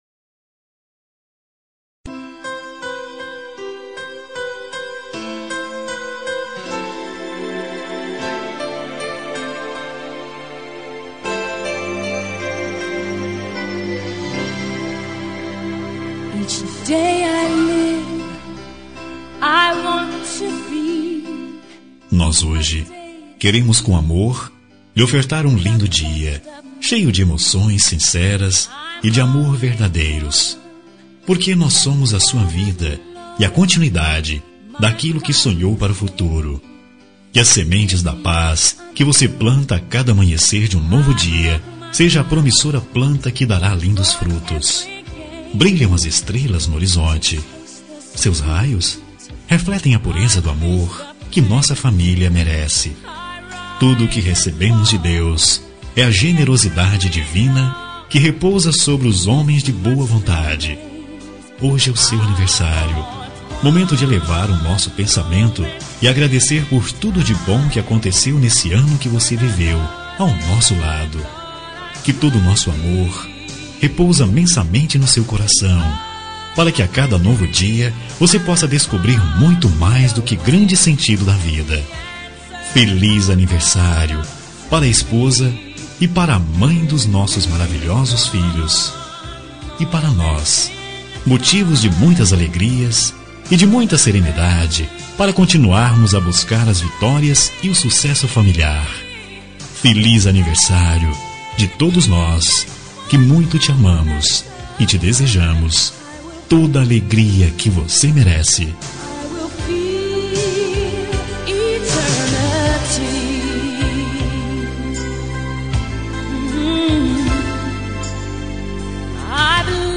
Telemensagem Aniversário de Esposa – Voz Masculina – Cód: 1118 – Marido e Filhos enviando